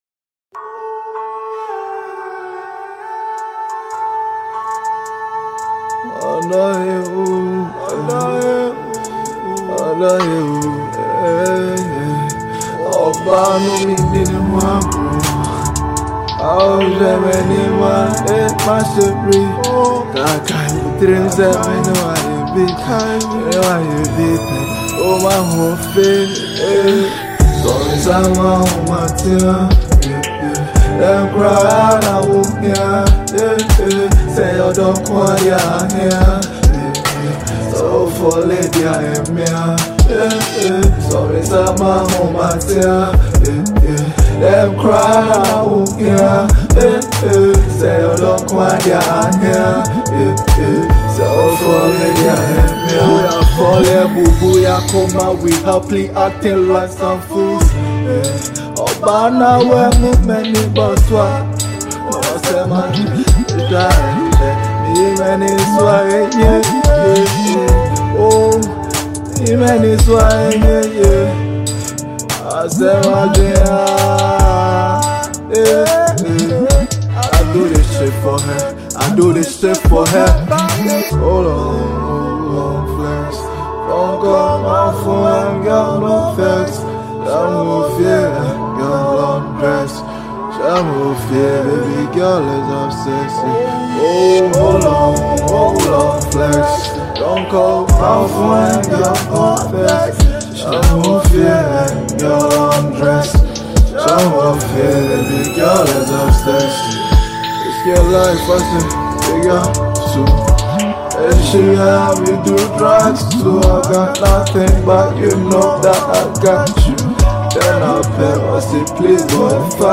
a Ghanaian Hiphop artiste